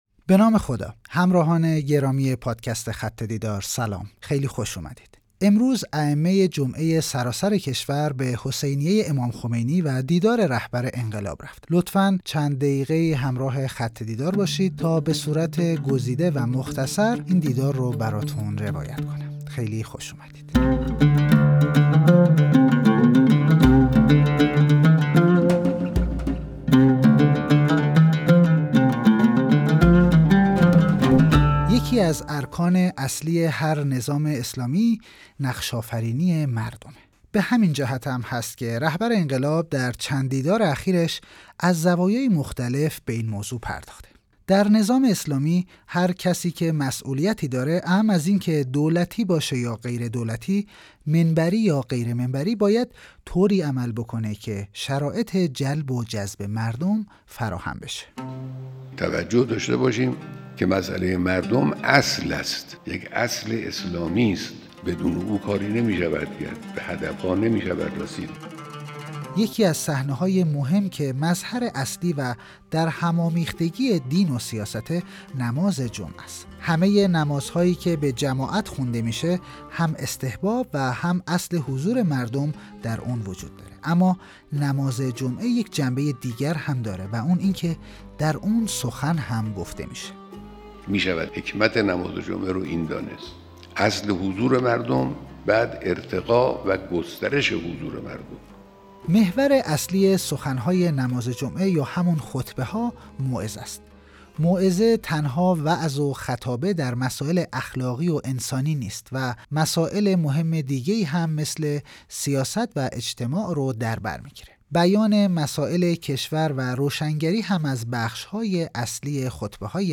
روایت صوتی خط دیدار